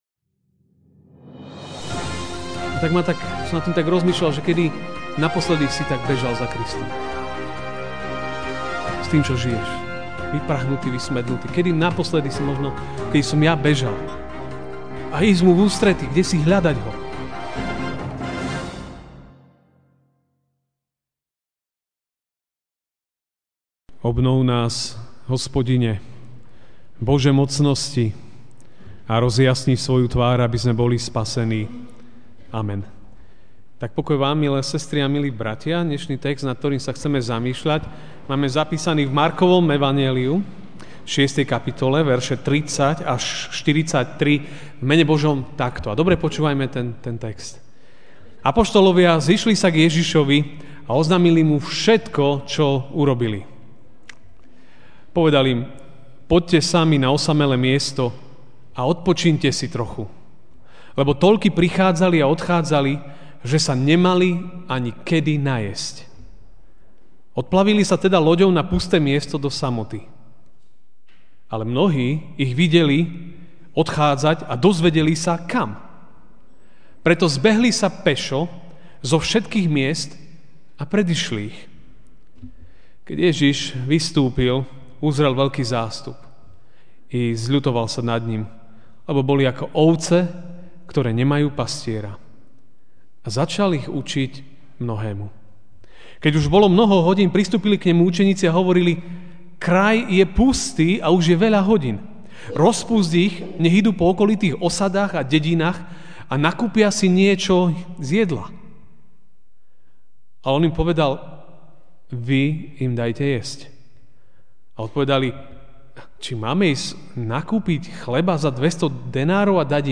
Ranná kázeň: Poďakovanie za úrodu (Marek 6, 30-43) Apoštoli sa zišli k Ježišovi a oznámili mu všetko, čo robili a učili.